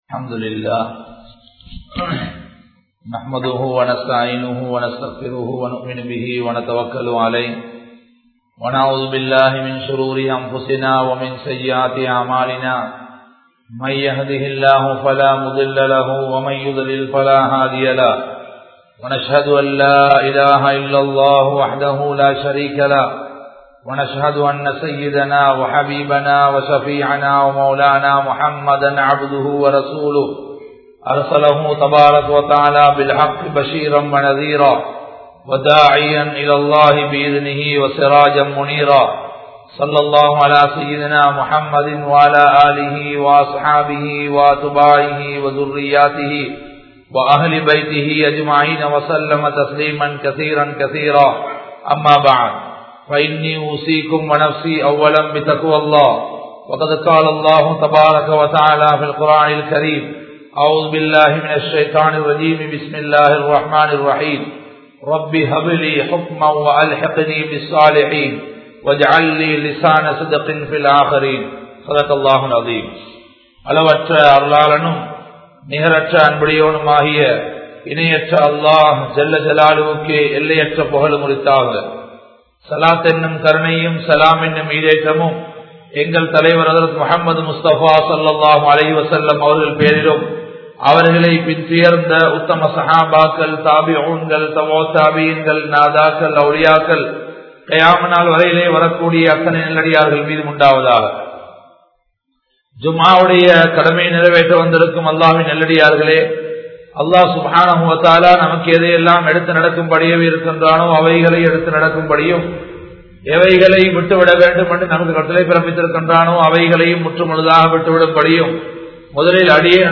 Yaarudan Natpu Kolla Vendum? (யாருடன் நட்பு கொள்ள வேண்டும்?) | Audio Bayans | All Ceylon Muslim Youth Community | Addalaichenai
Gorakana Jumuah Masjith